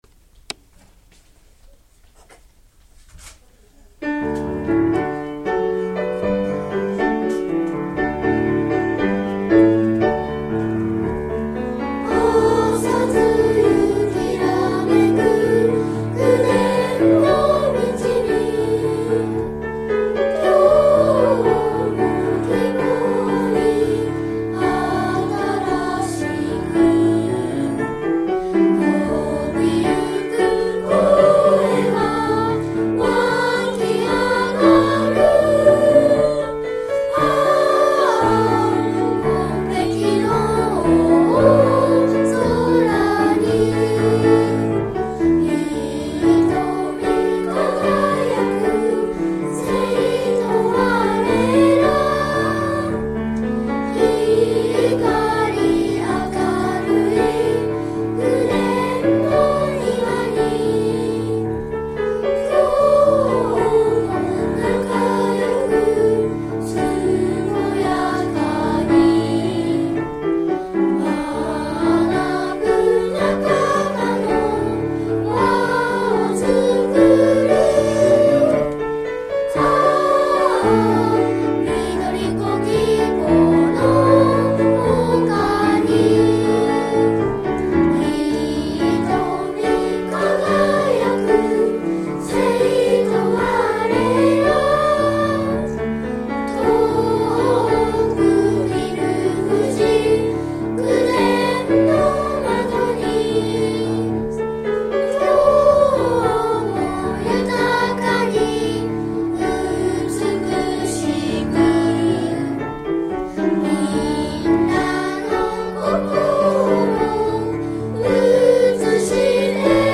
本校児童による校歌歌唱を聴く